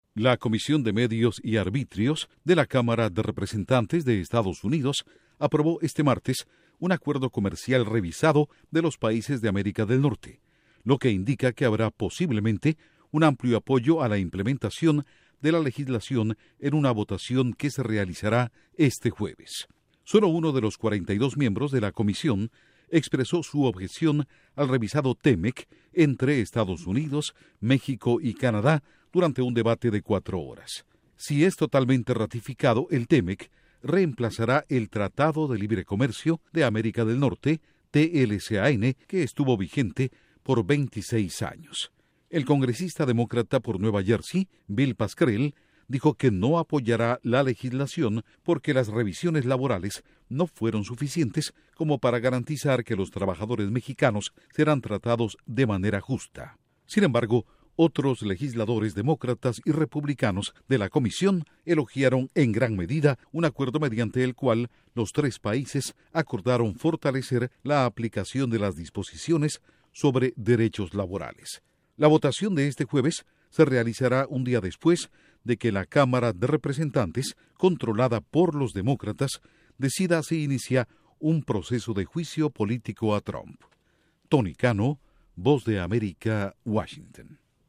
Comisión de Cámara de Representantes de EE.UU. avanza en acuerdo comercial TMEC. Informa desde la Voz de América en Washington